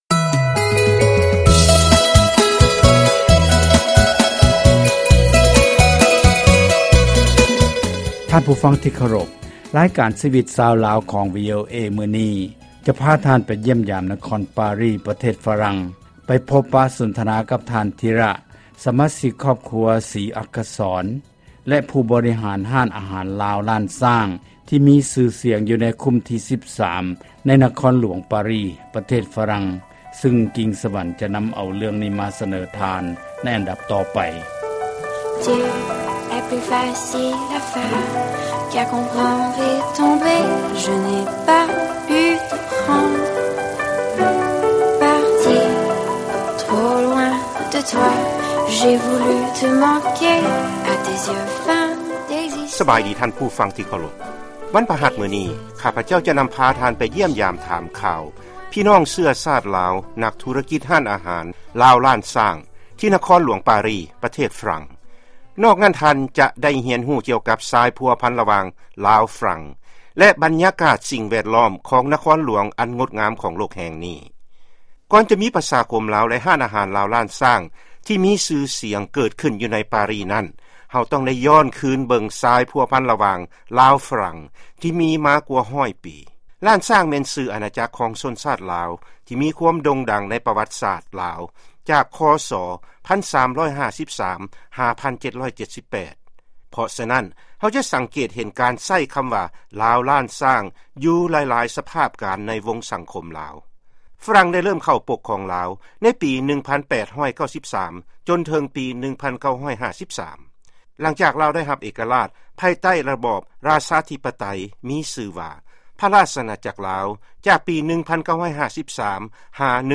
ຟັງການສຳພາດ ຮ້ານອາຫານ ລາວລ້ານຊ້າງ ທີນະຄອນຫລວງປາຣີ